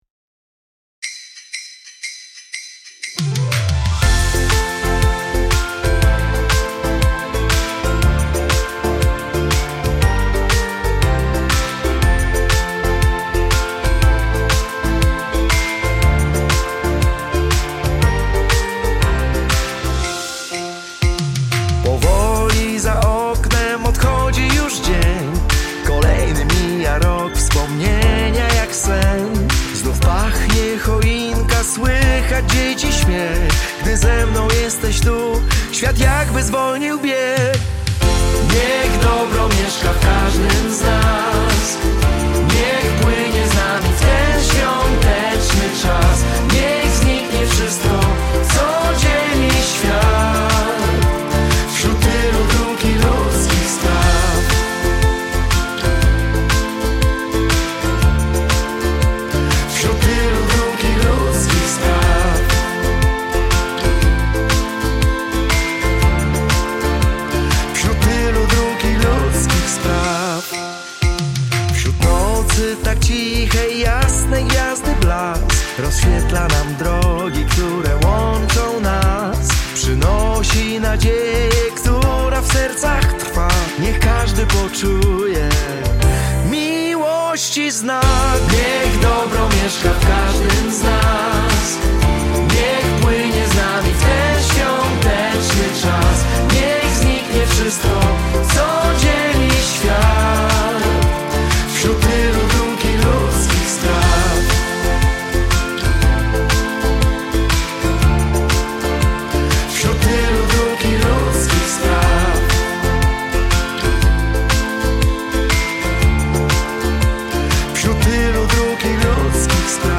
Sesja nagraniowa pastorałki